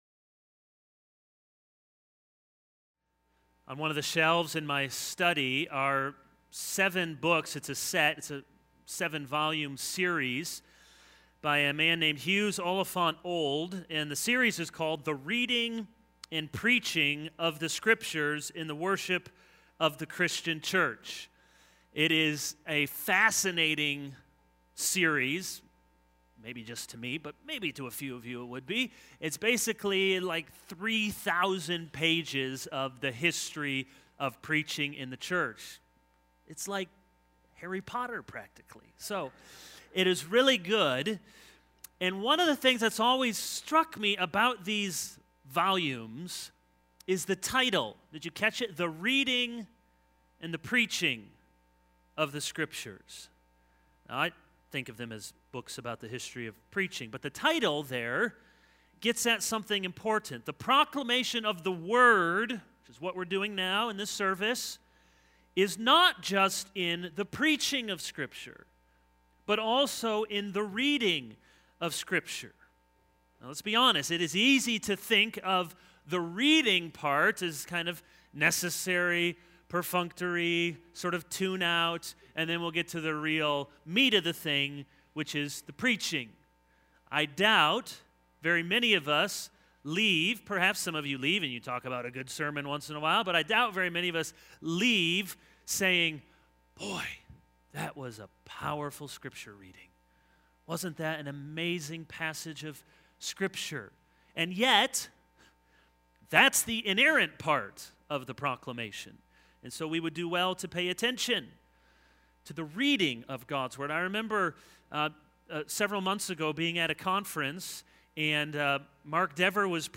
This is a sermon on Exodus 8:1-9:12.